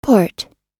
Port /pɔːt/
port__us_1.mp3